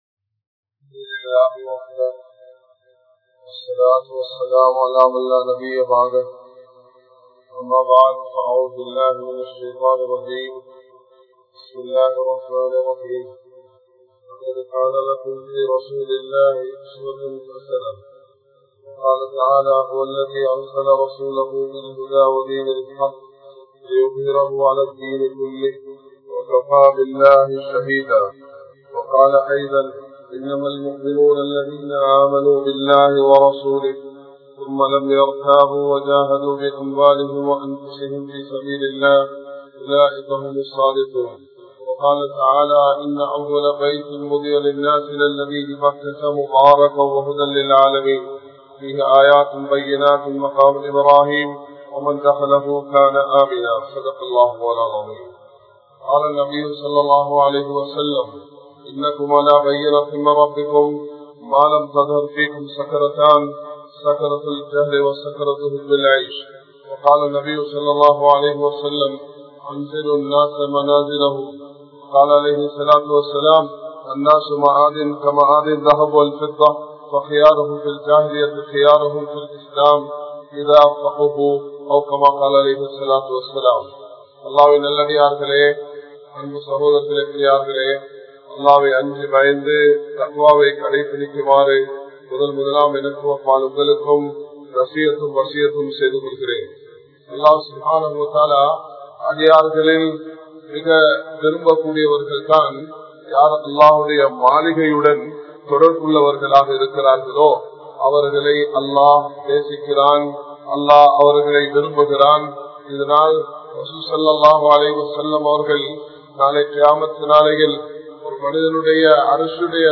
Ahlaaq & Dhikkr | Audio Bayans | All Ceylon Muslim Youth Community | Addalaichenai